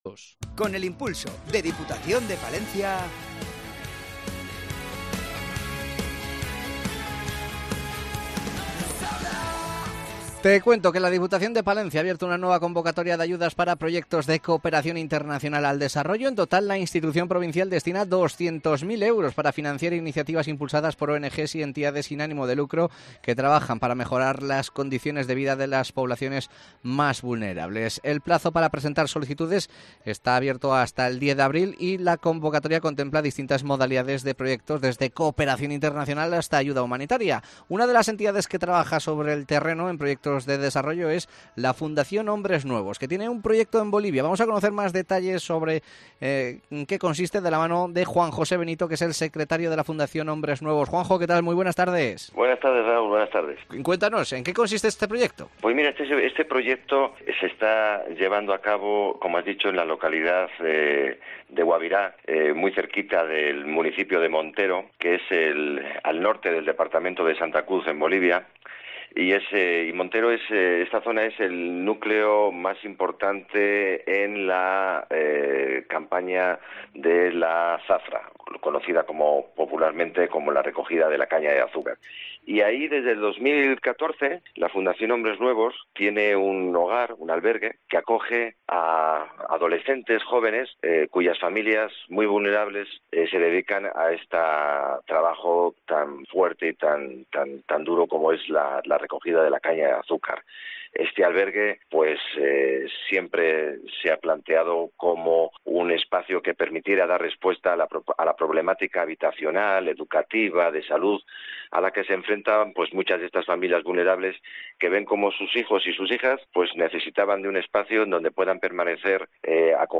Ayudas a la cooperación y entrevista a la fundación Hombres Nuevos